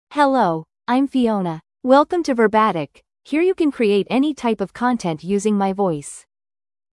FemaleEnglish (United States)
Fiona is a female AI voice for English (United States).
Voice sample
Listen to Fiona's female English voice.
Fiona delivers clear pronunciation with authentic United States English intonation, making your content sound professionally produced.